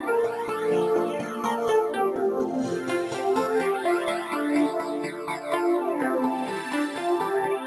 Pascal_F_125_Dry.wav